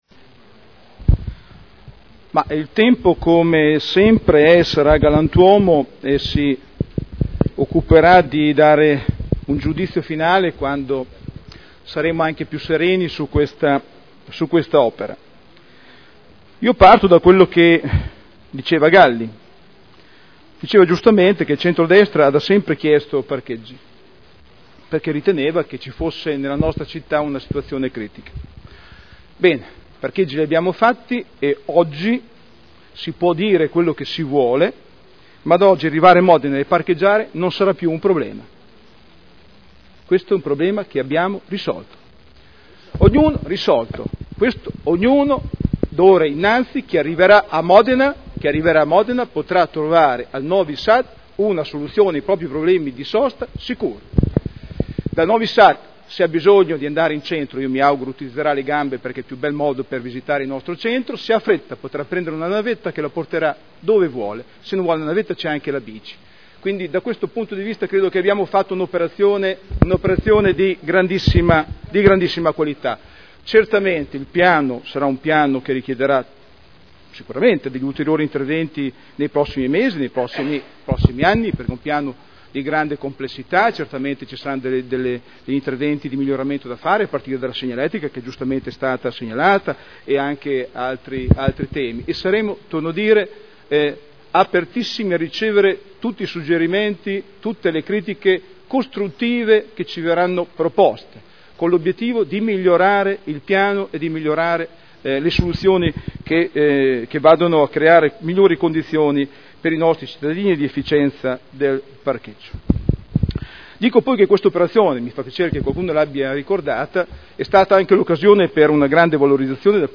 Daniele Sitta — Sito Audio Consiglio Comunale